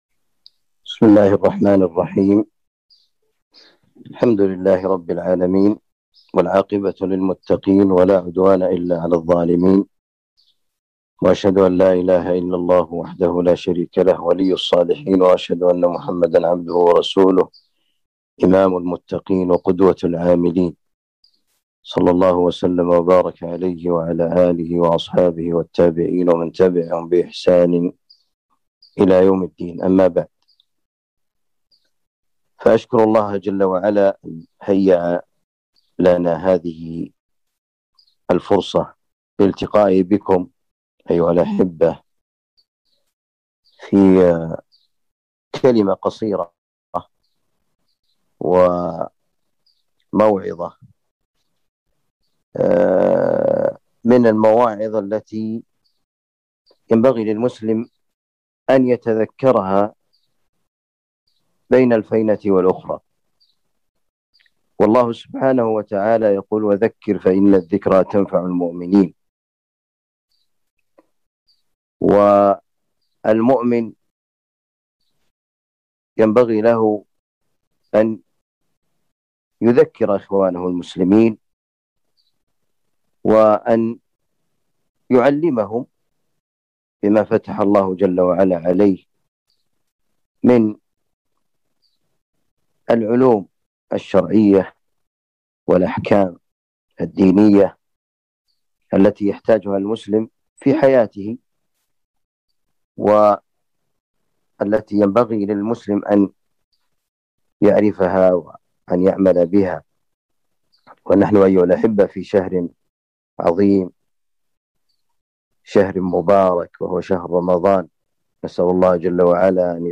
كلمة بعنوان آداب الدعاء وارتباطه بالصيام